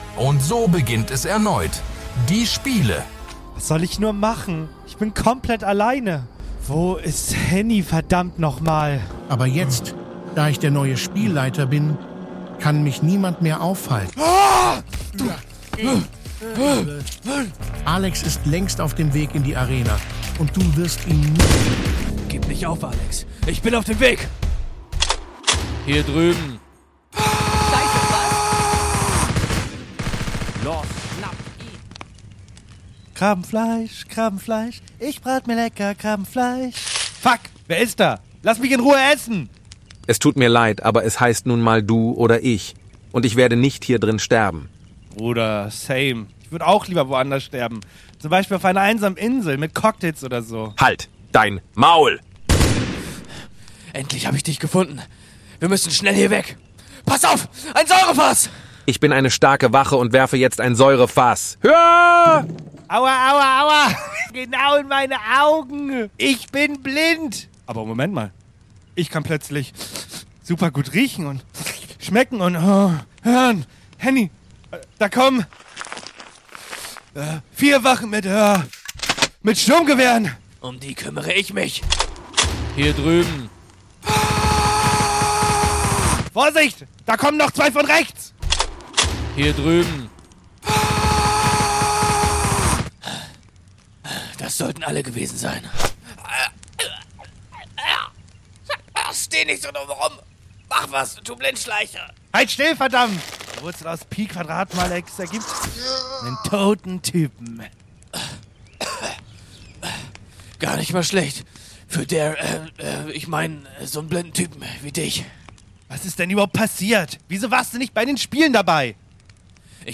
Erstmals gibt es brandheiße Outtakes von unseren Intro-Aufnahmen!